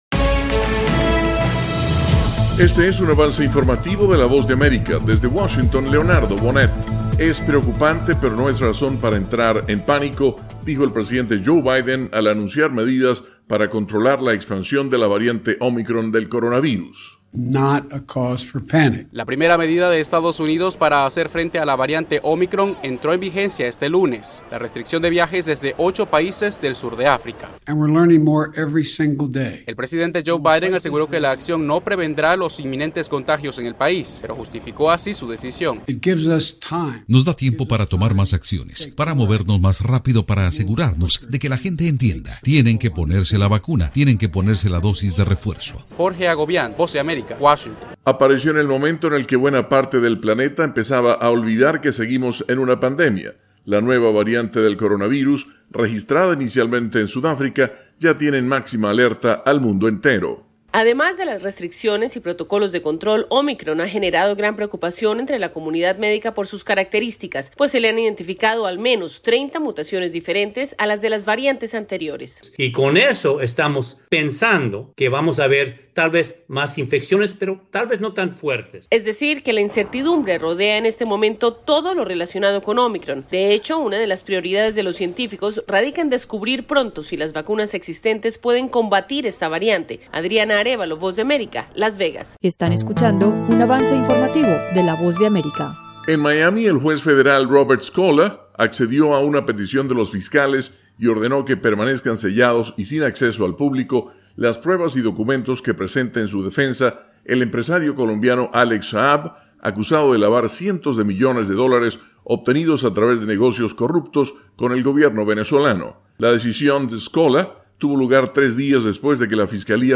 Resumen informativo con algunas de las noticias más importantes de Estados Unidos y el resto del mundo.